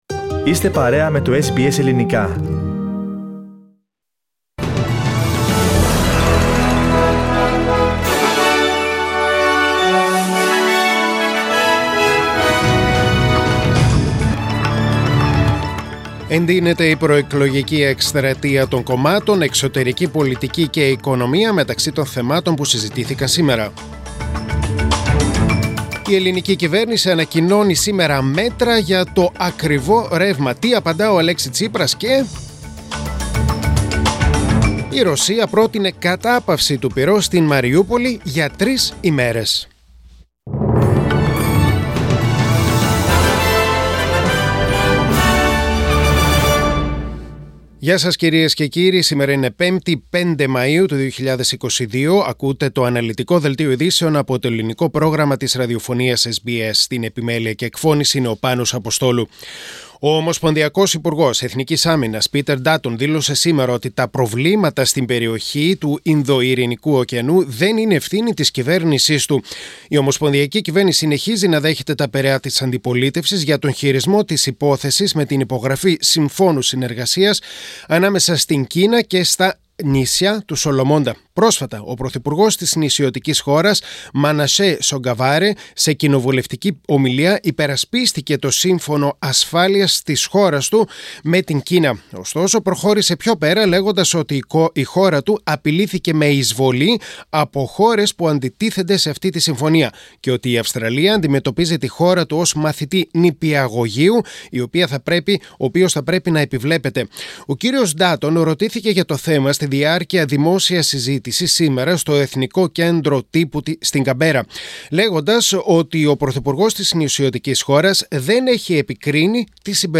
Δελτίο Ειδήσεων: Πέμπτη 5.5.2022